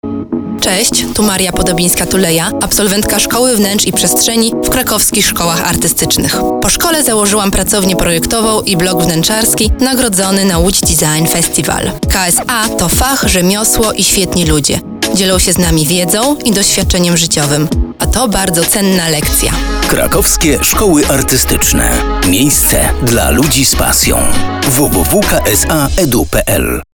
Wspólnie z kolegami z Krakowskich Szkół Artystycznych nagraliśmy  w Radio Eska spoty reklamowe opowiadające o KSA.